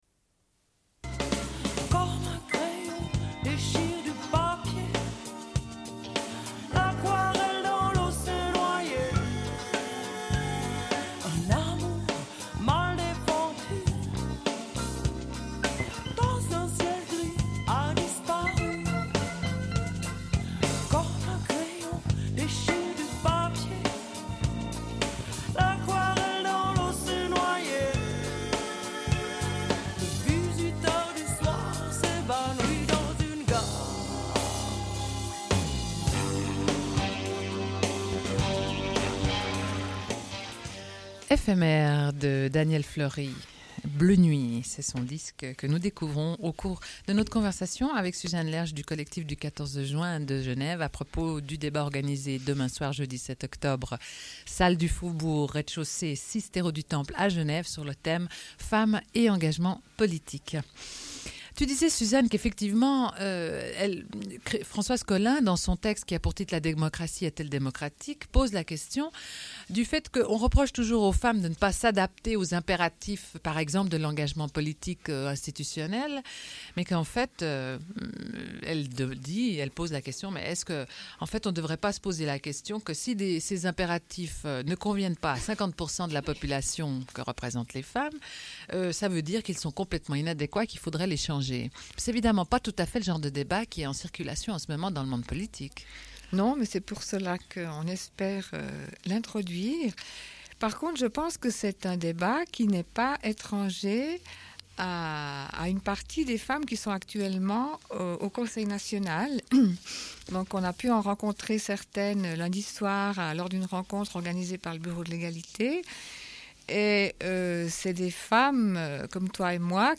Une cassette audio, face A31:38